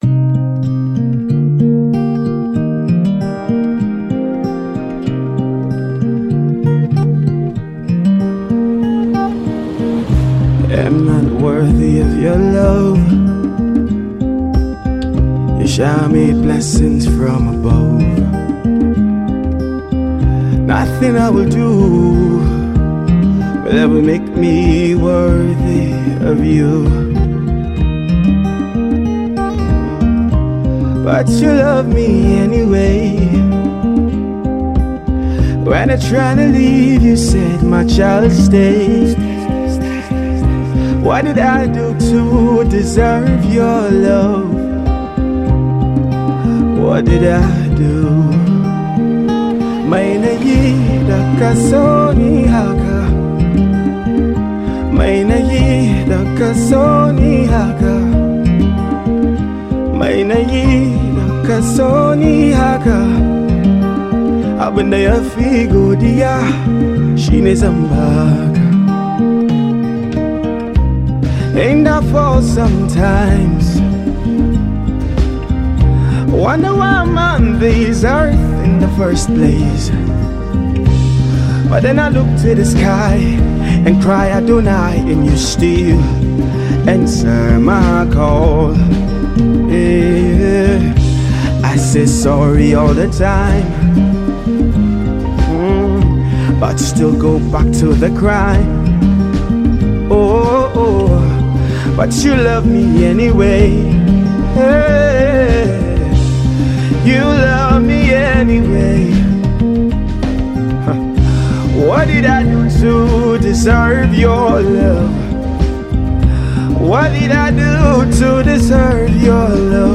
this song is lased with an hausa chorus
Gospel Artist